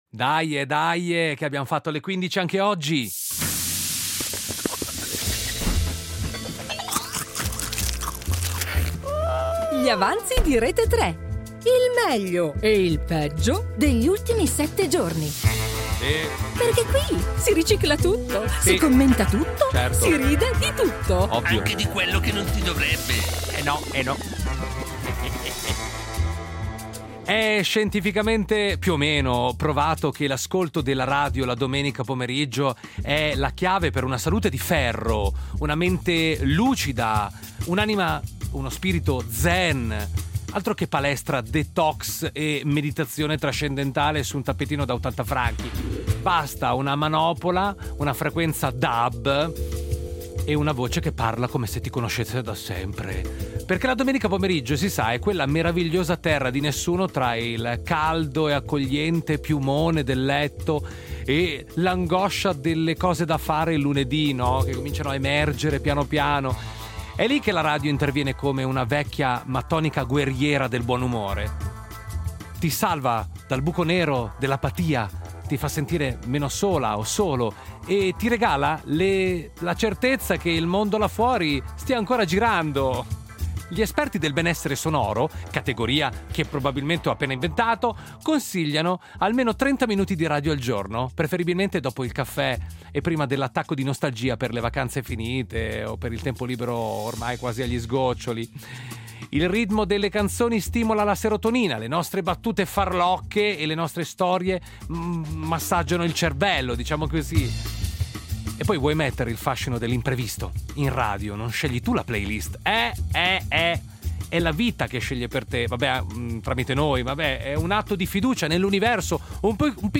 Il pranzo della domenica è finito… ma in radio restano Gli avanzi.
Due ore di musica, momenti memorabili (o dimenticabili), notizie che hanno fatto rumore e altre che hanno solo fatto vibrare il telefono. Un programma che non butta via niente: si ricicla tutto, si commenta tutto, si ride di tutto.